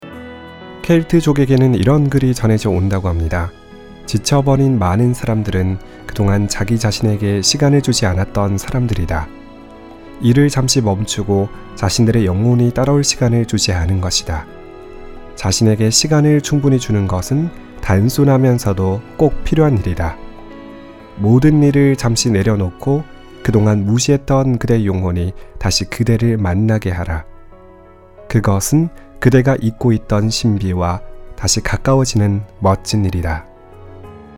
解说旁白